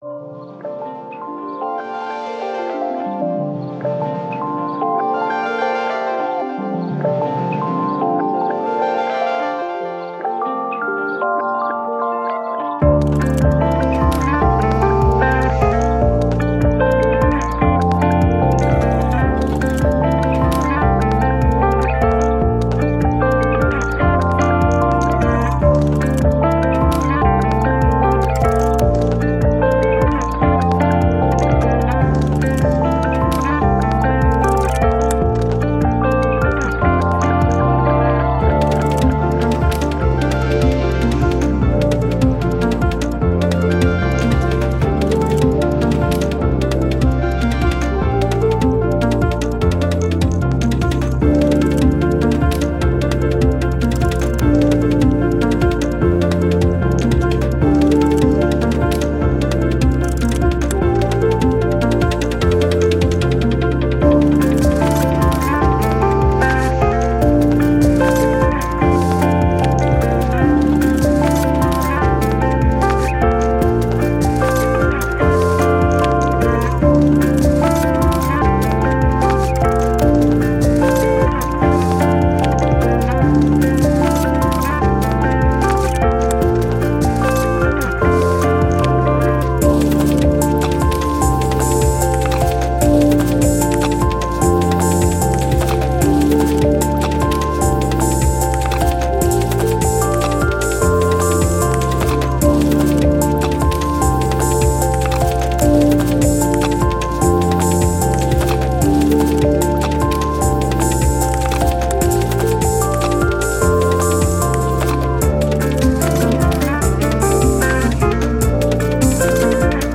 Ambient, Electronic, Chilled, Story, Glitch